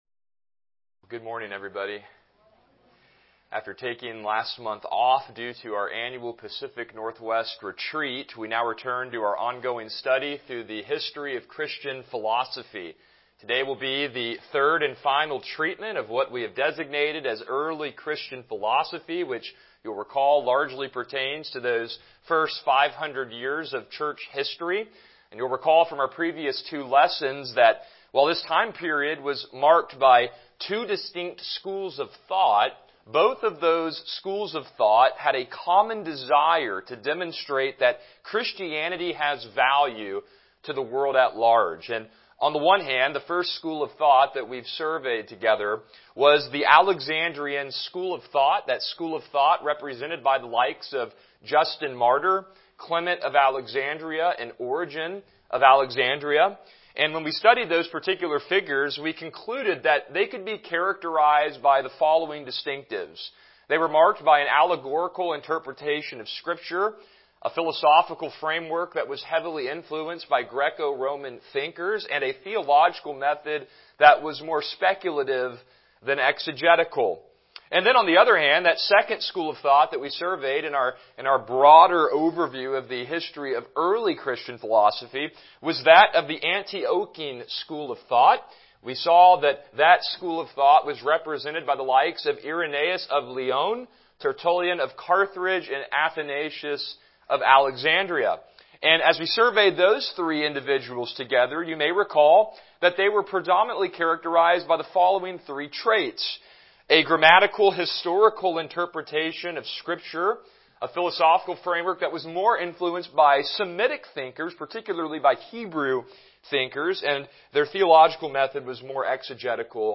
Series: Christianity and Philosophy Service Type: Sunday School « Come and See